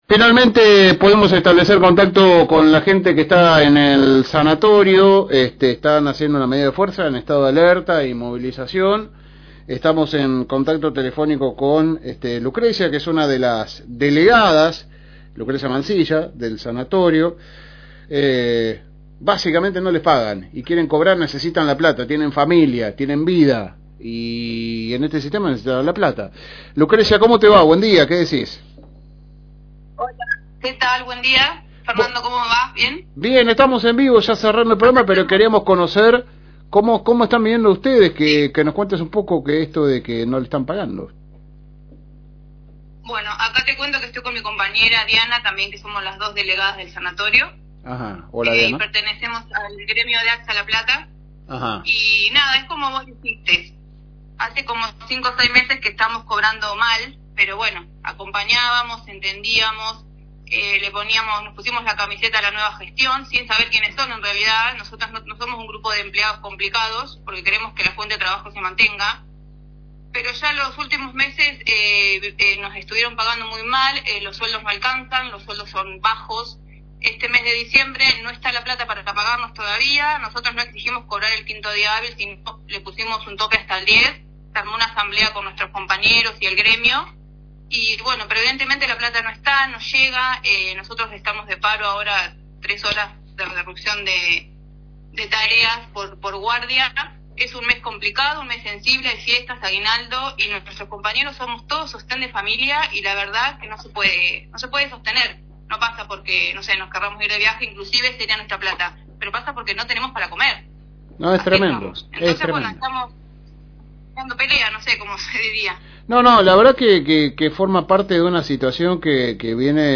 Charlamos con